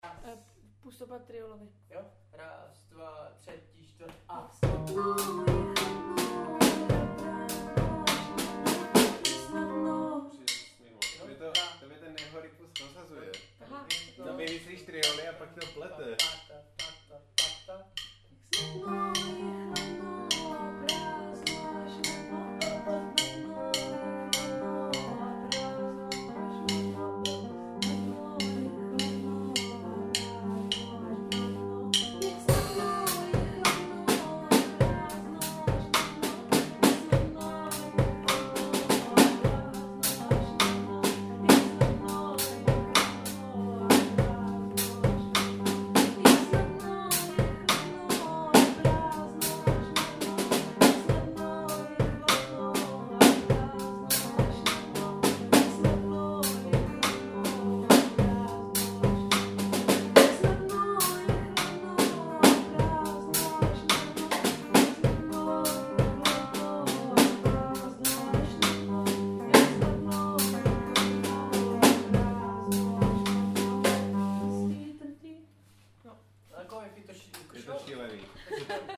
STE-188_pustopad_trioly.mp3